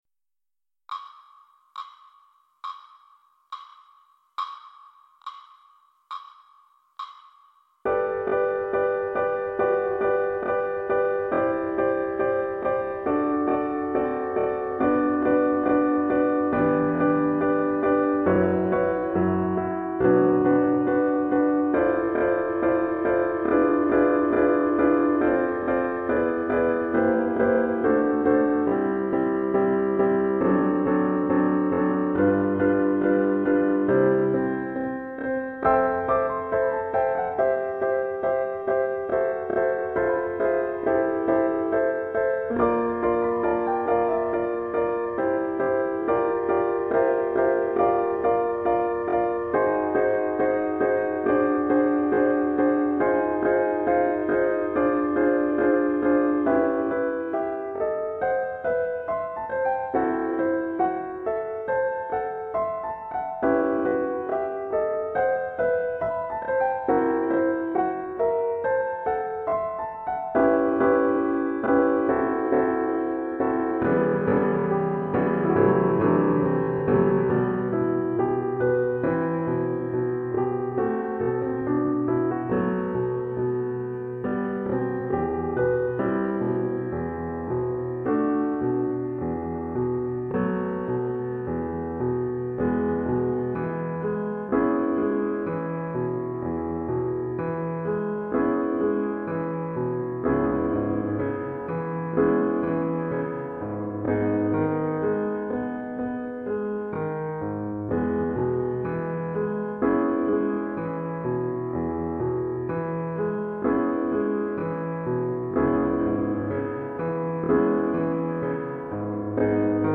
Sonatine Piano Part